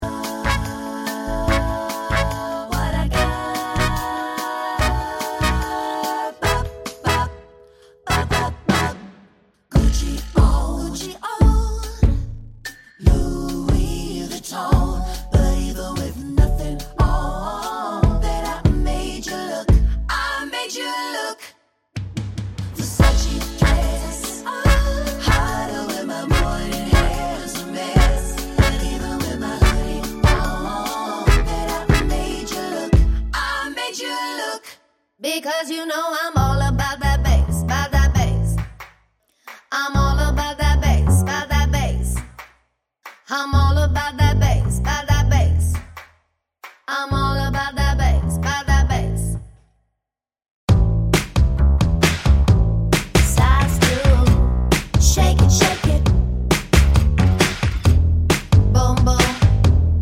(Medley) Pop (2020s)